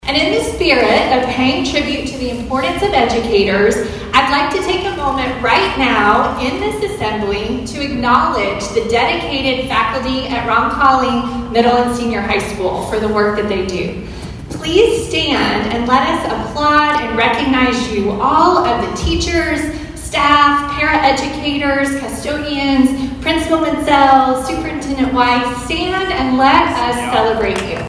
ABERDEEN, S.D.(HubCityRadio)- Students and faculty were in for a surprise of lifetime Thursday at Aberdeen Roncalli Middle & High School.